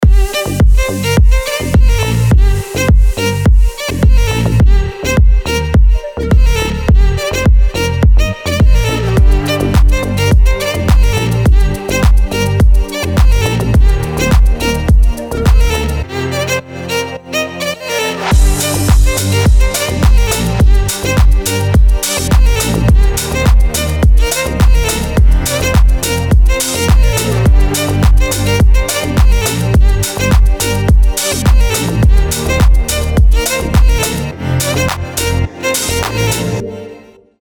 восточные мотивы
без слов
красивая мелодия
скрипка
Melodic
Стиль: deep house